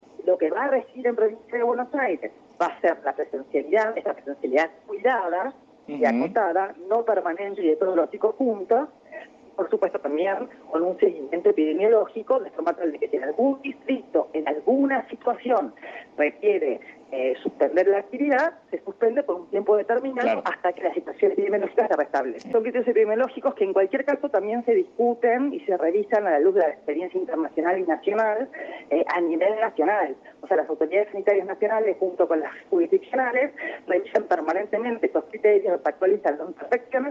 Declaraciones de Agustina Vila en Radio AM Provincia